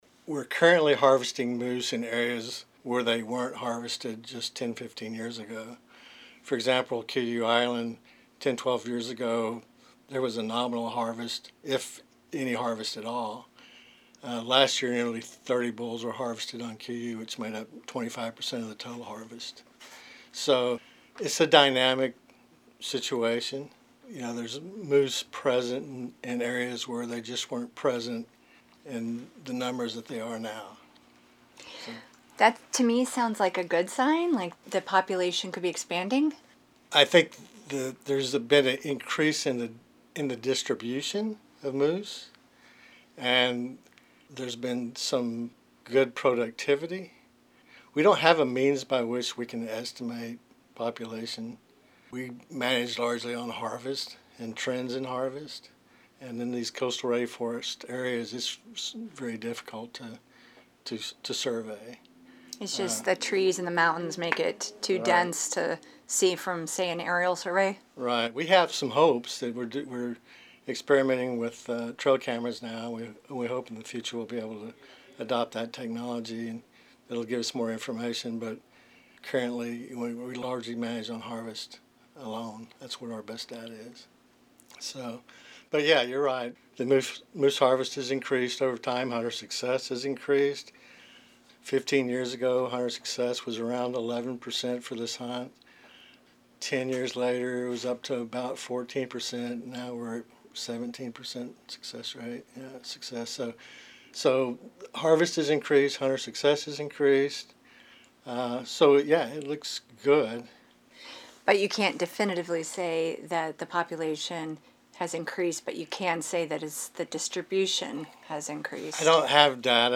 He says the hunt has been successful by all accounts.